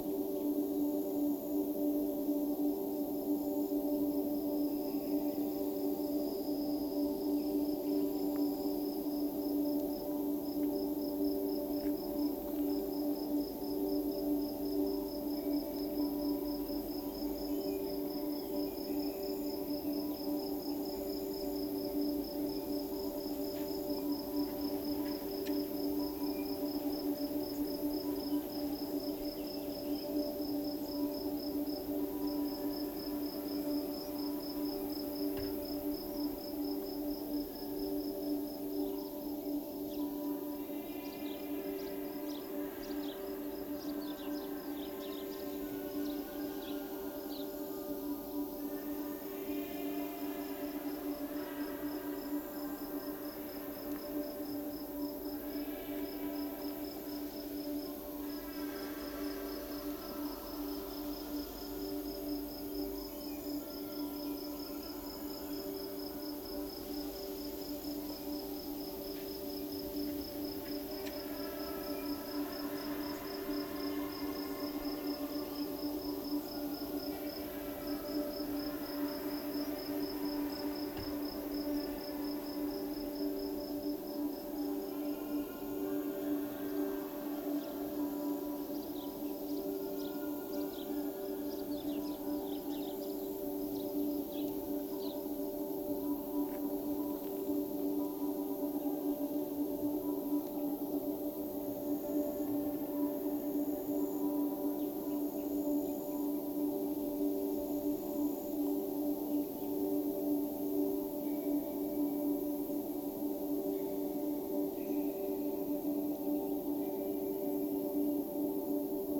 ambient_sfx.wav